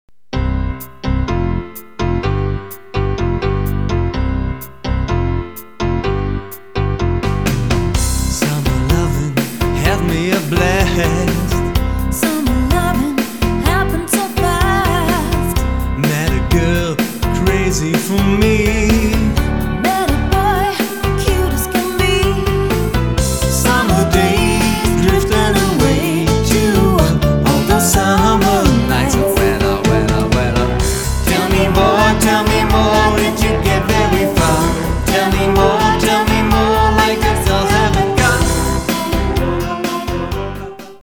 Tanz und Unterhaltungsmusik
• Coverband